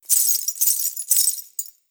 Danza árabe, bailarina mueve un sujetador con monedas 03: ritmo
agitar
moneda
Sonidos: Acciones humanas